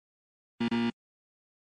Download Windows Error sound effect for free.
Windows Error